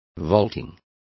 Complete with pronunciation of the translation of vaulting.